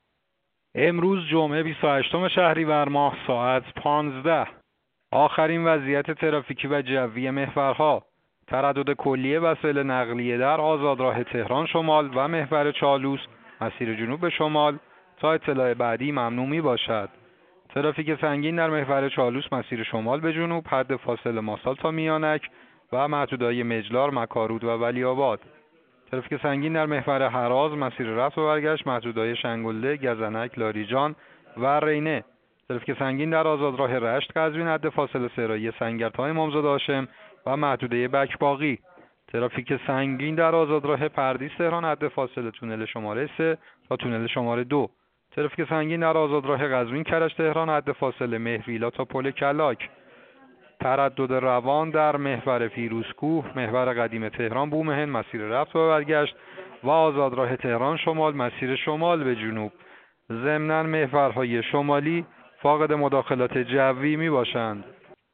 گزارش رادیو اینترنتی از آخرین وضعیت ترافیکی جاده‌ها ساعت ۱۵ بیست و هشتم شهریور؛